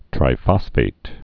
(trī-fŏsfāt)